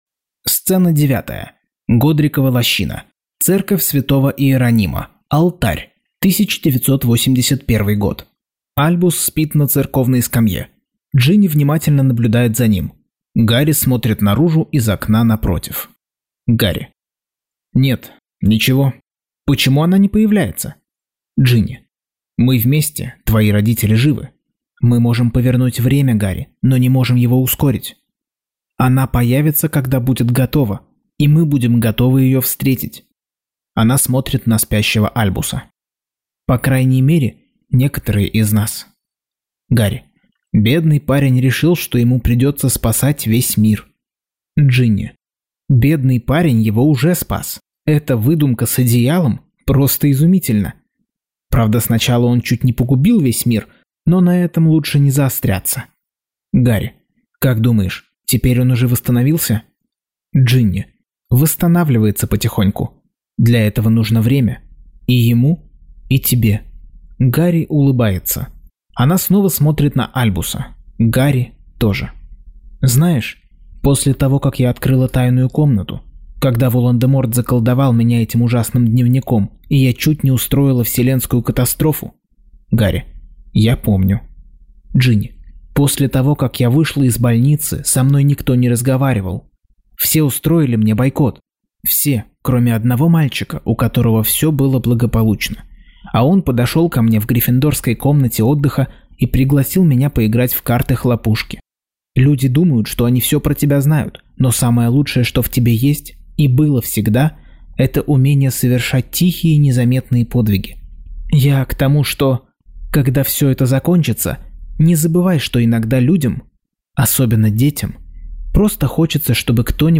Аудиокнига Гарри Поттер и проклятое дитя. Часть 60.